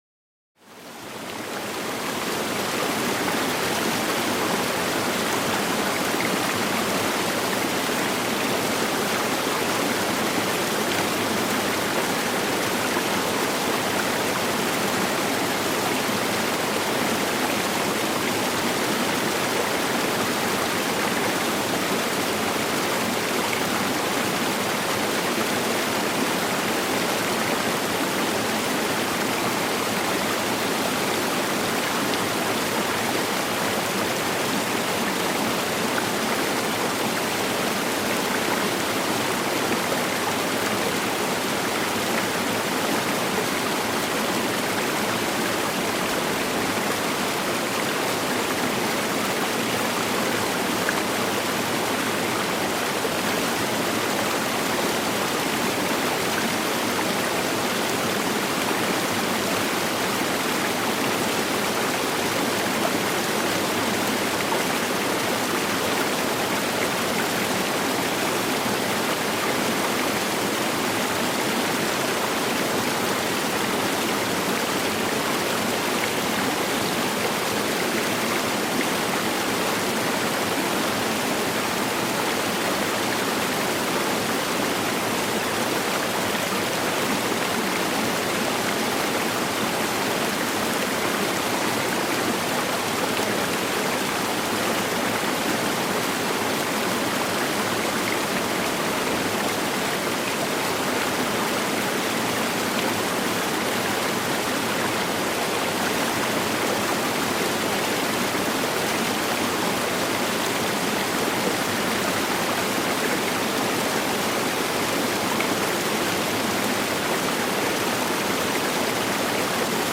GEIST-BERUHIGUNG: Gebirgsbach-Tropfen mit sanftem Felsentropfen